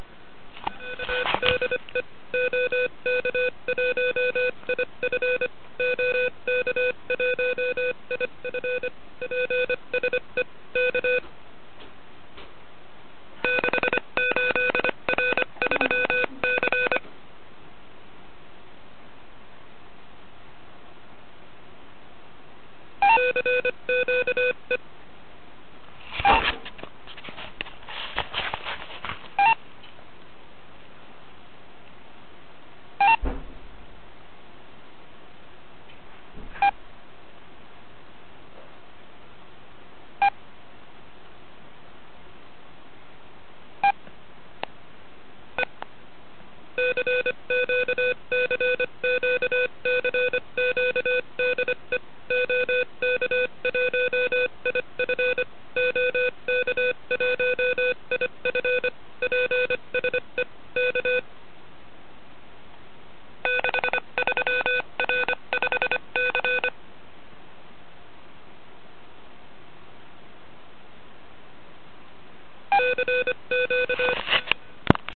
Pro lepš� pochopen� funkce ochrany PA tranzistoru před přehř�t�m, jsem tento stav nasimuloval. Na n�že uveden� nahr�vce tedy m�te nahr�no to, co oper�tor uslyš� ve sluch�tk�ch (samozřejmě se to nevys�l� ven). 1.
Upozorněn� se provede trilkem, kter� ř�k�, že teplota je vyšš� než 66C.
Mezit�m se teplota sn�žovala a tak od trilku se změnila jen na jeden t�n, což upozorňuje na teplotu vyšš� než 62C, ale nižš� jak 66C. 5.